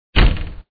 8_s_byt_dvere.mp3